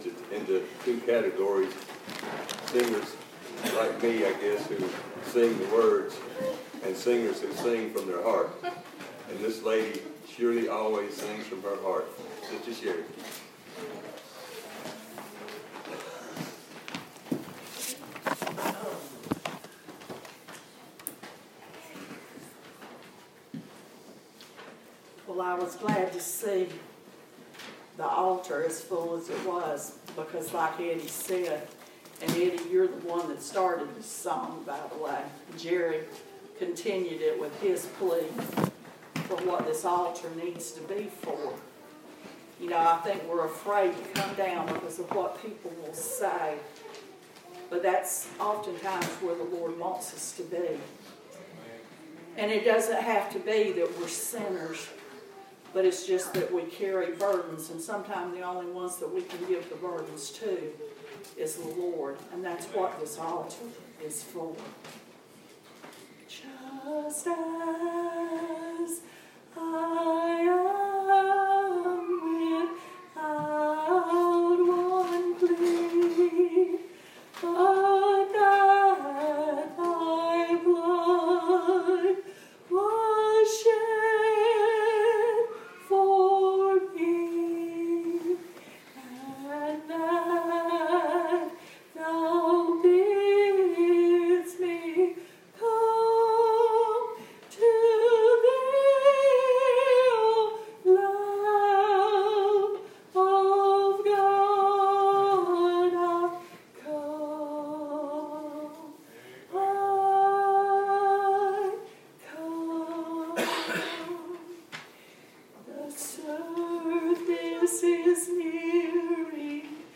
Audio of Sermon